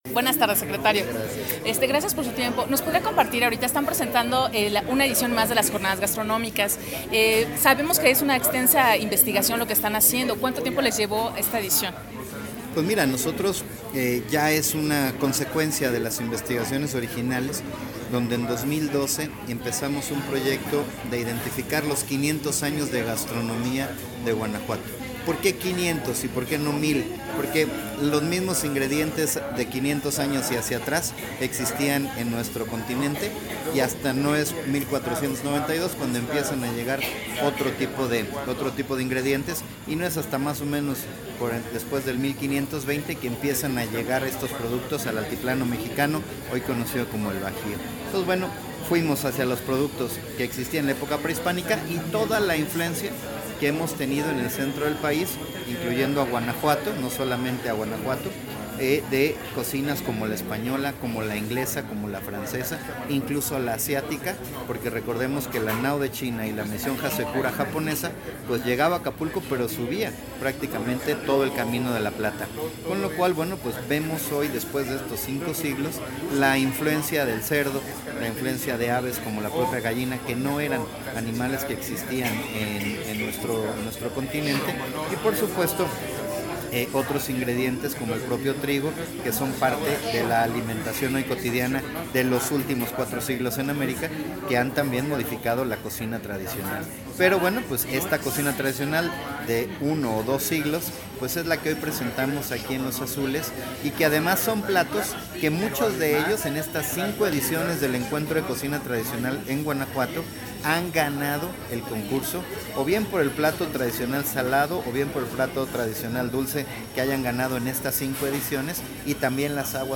Asimismo, en entrevista exclusiva para el Periódico Opciones, el funcionario ahondó en el tema de las jornadas gastronómicas:
EntrevistaFernandoOliveraRochaeEDIT.mp3